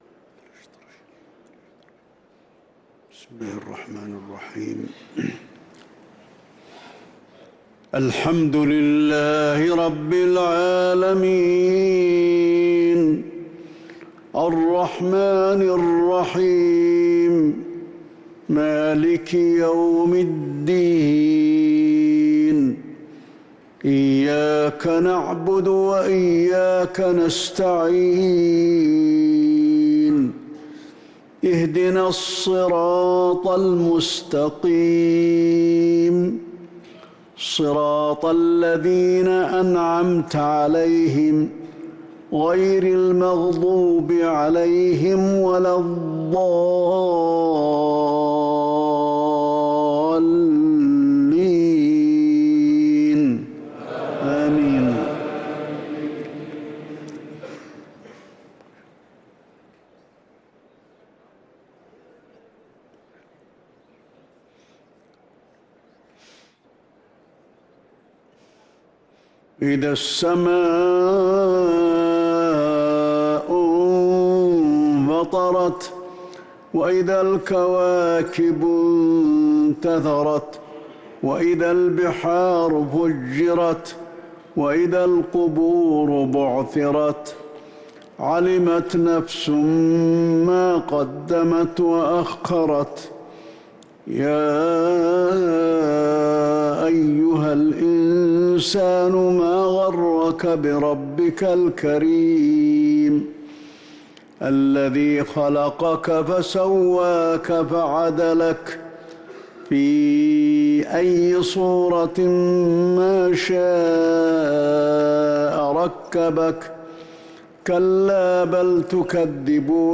صلاة العشاء للقارئ علي الحذيفي 5 جمادي الآخر 1445 هـ
تِلَاوَات الْحَرَمَيْن .